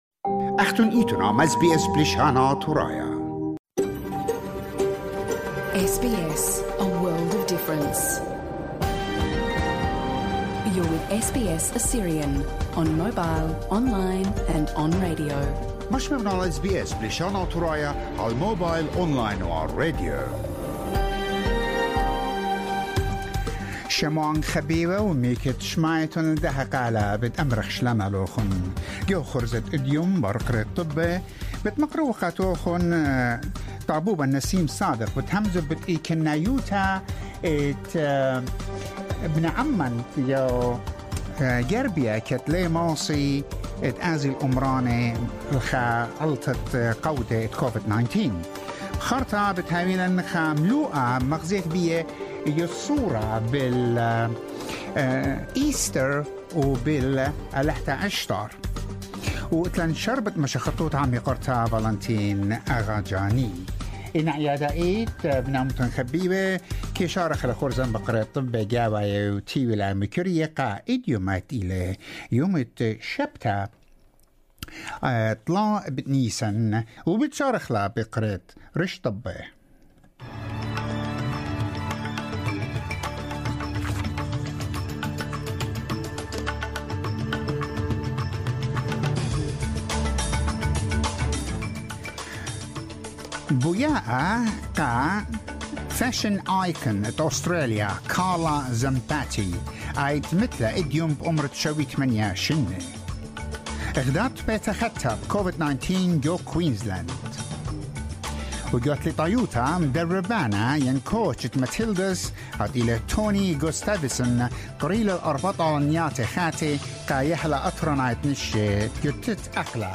SBS NEWS IN ASSYRIAN